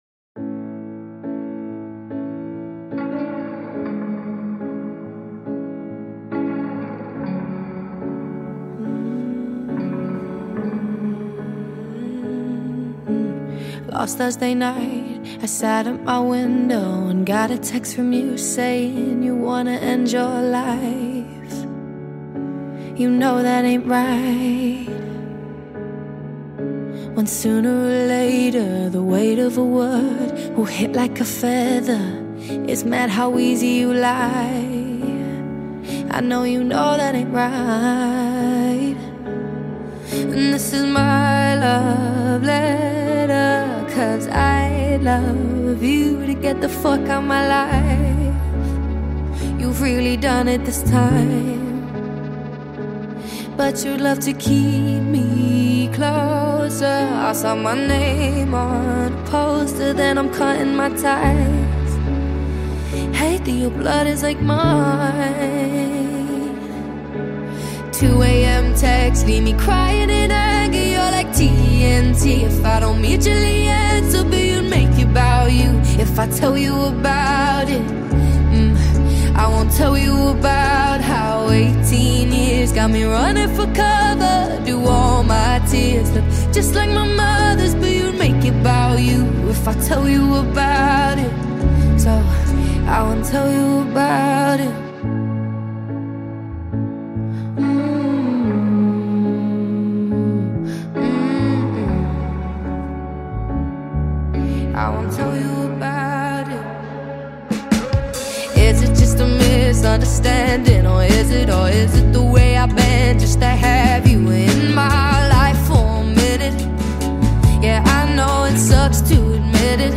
strong vocals, emotional depth